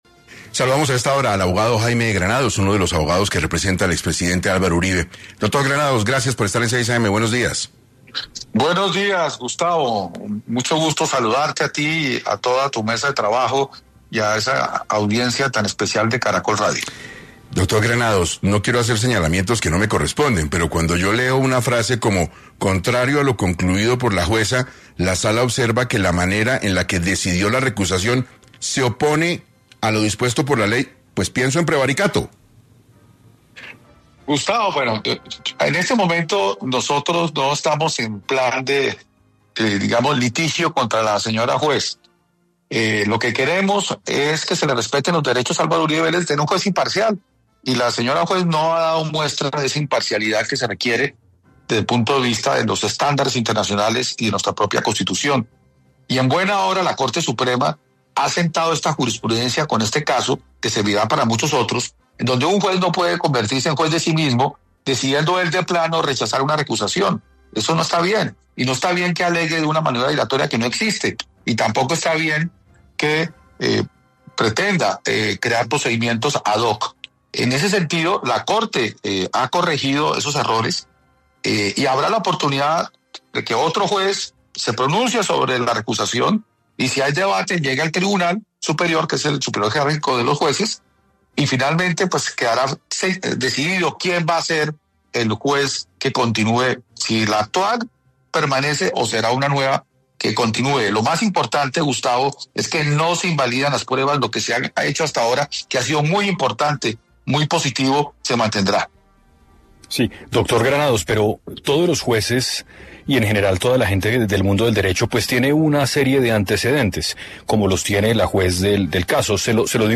En 6AM de Caracol Radio